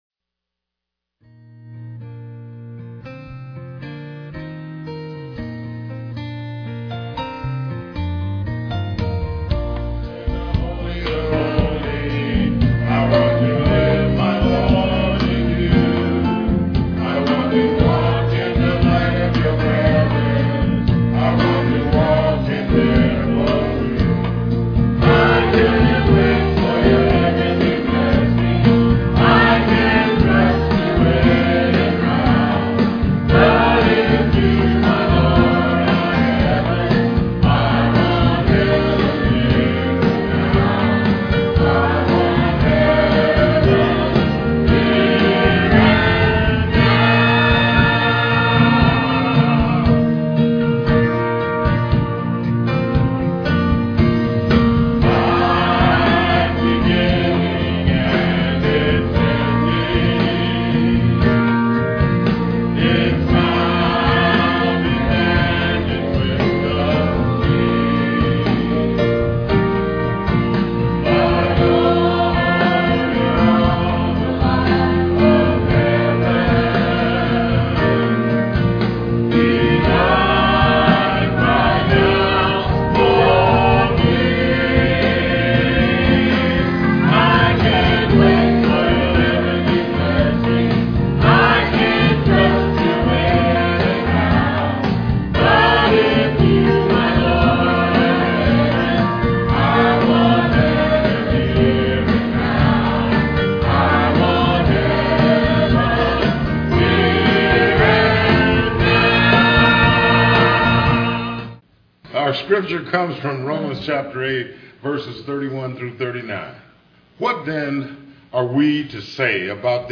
Vocal solo
Piano and organ duet